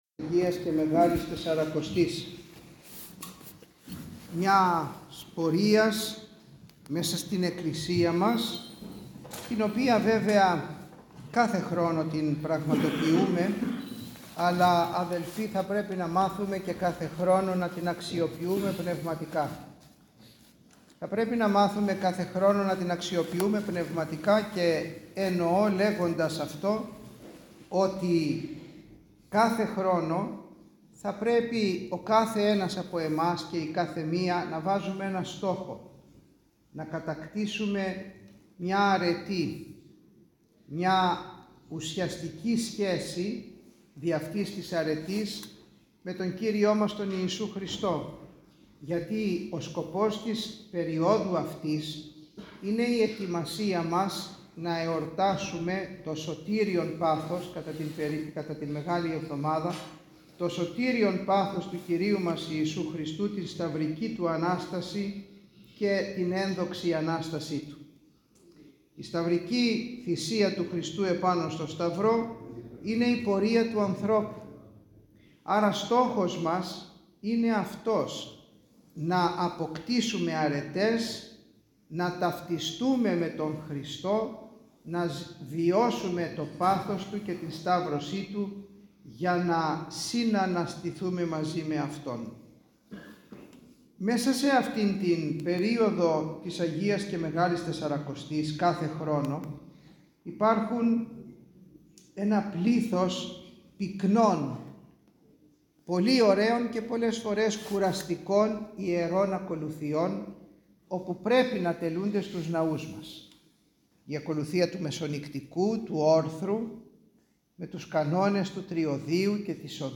Ο Σεβασμιώτατος Μητροπολίτης Θεσσαλιώτιδος και Φαναριοφερσάλων κ. Τιμόθεος τέλεσε την ακολουθία των Προηγιασμένων Τιμίων Δώρων στον Ιερό Ναό Αγίου Χαραλάμπους Παλαμά την Τετάρτη 3 Απριλίου 2024.